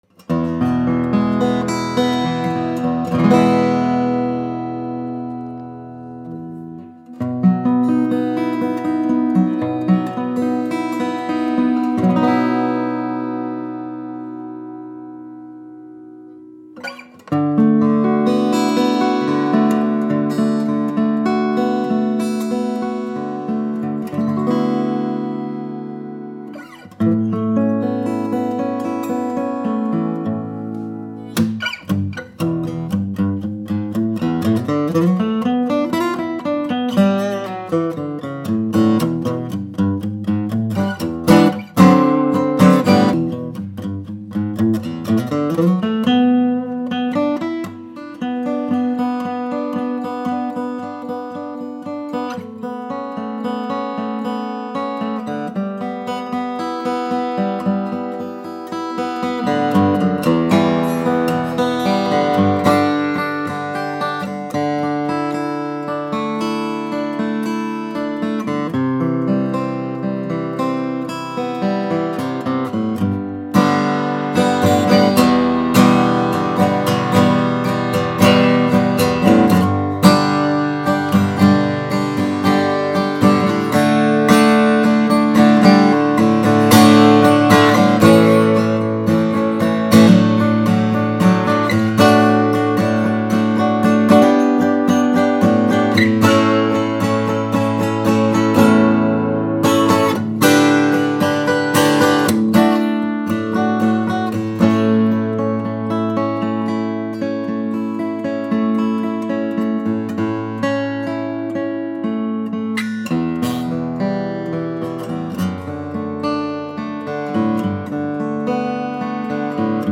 Beautiful Santa Cruz 1934 OM-42 Custom ‘Bryn Anthem’ build with pre-war ‘Bryn Anthem’ Indian Rosewood and Adirondack Red Spruce offering that pure and refined tone with those crystalline mids and highs that only pre-war guitars offer.
This Santa Cruz 1934 OM-42 Custom ‘Bryn Anthem’ is delightfully versatile and resonates even with the lightest touch.
While today’s Indian Rosewood offers a little slower response, warmth and more blended tone, this Rosewood is brighter, clearer more articulate.